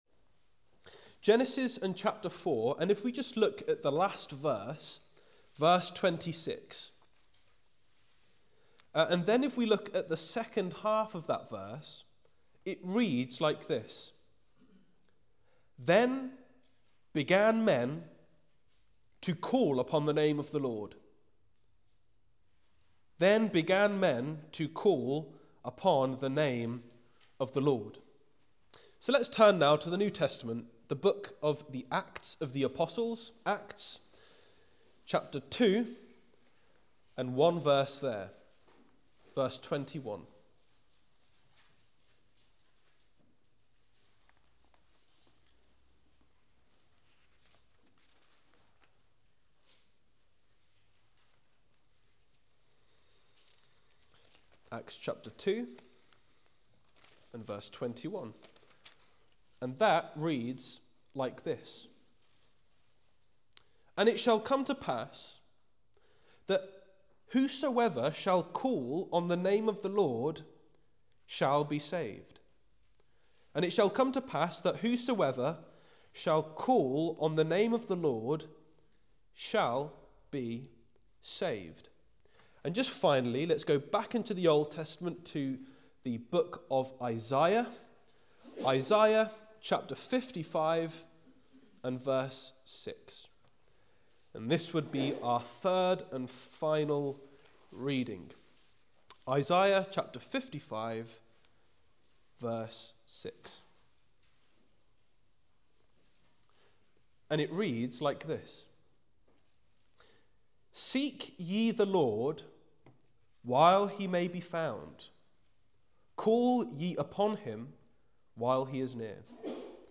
Gospel Meetings